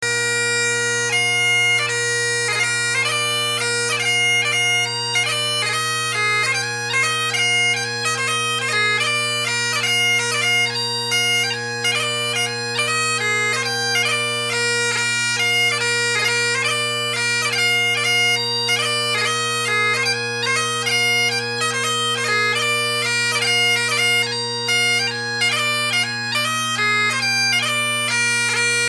Heritage drones with a Medallist pipe chanter
2/4 march: